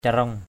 /ca-rɔŋ/ (d.) chông = pieu, pointe effilée. phaw caraong f| c_r” súng và chông (súng ống.)